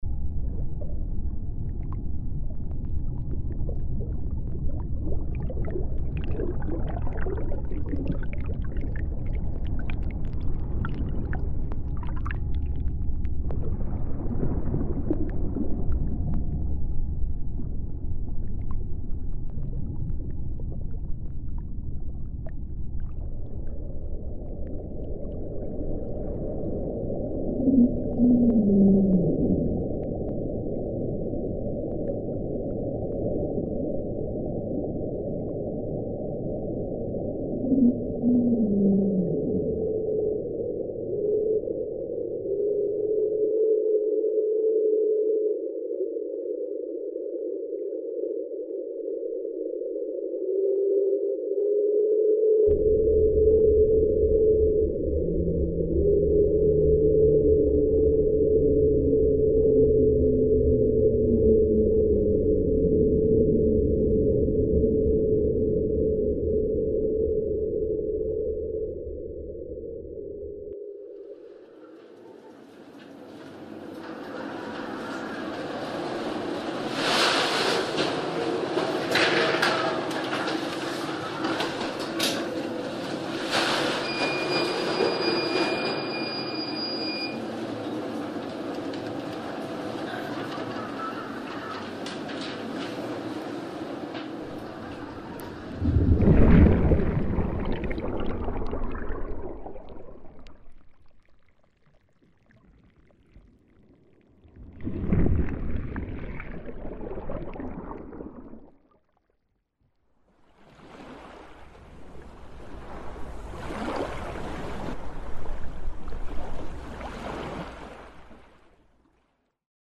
Inspired by real underwater recordings, this design translates the powerful and mysterious sounds of the sea. From the vast stillness of the deep sea to the haunting songs of whales, the distant hum of a cargo ship, and the gentle crash of surface waves
mar_mixagem.mp3